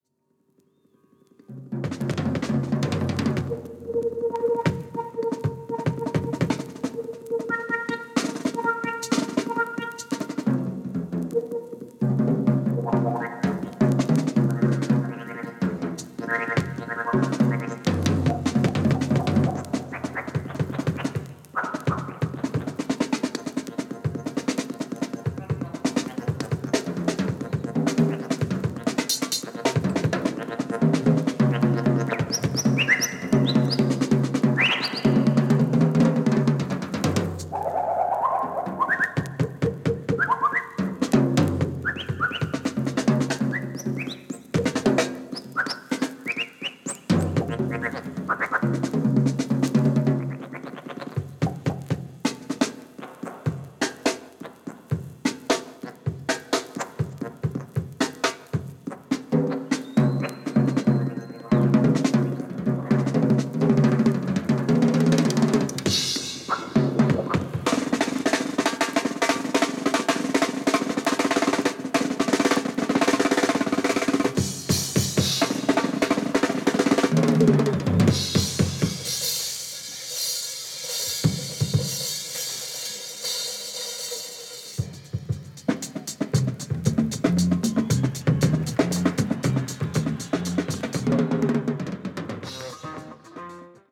2枚組ライブ盤です。